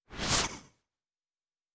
Synth Whoosh 4_4.wav